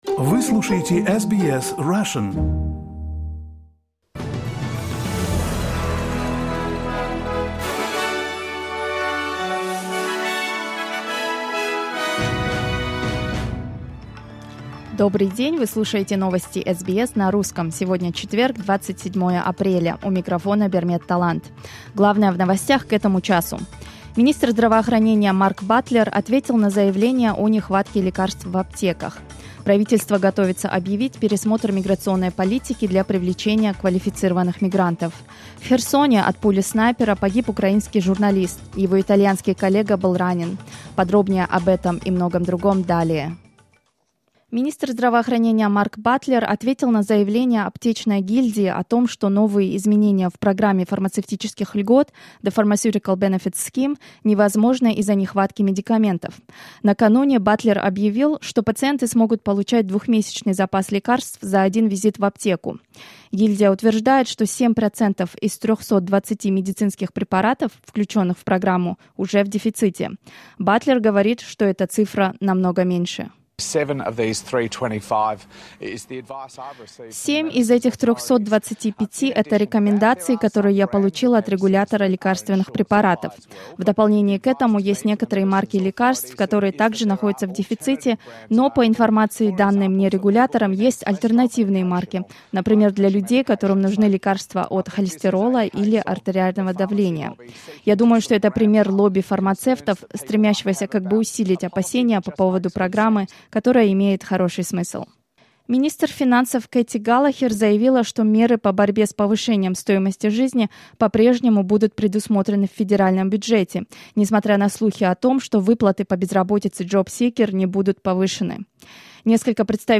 SBS news in Russian — 27.04.2023